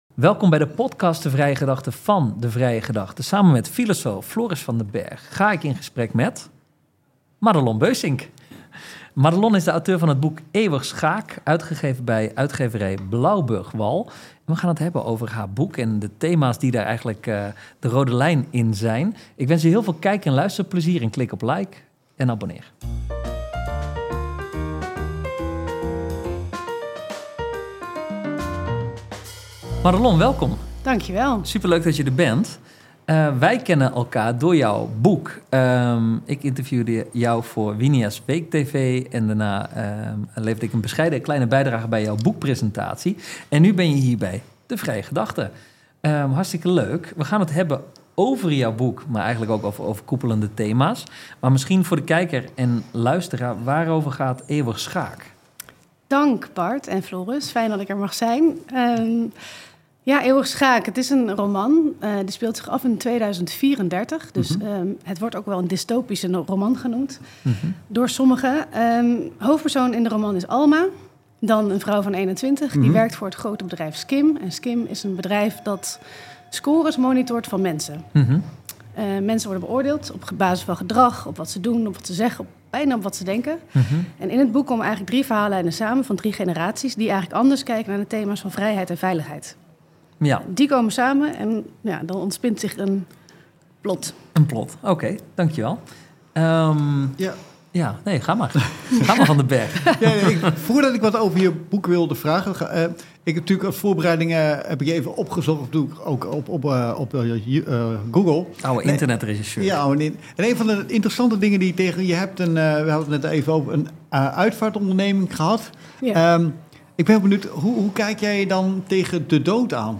Deze podcasts zijn opgenomen met beeld.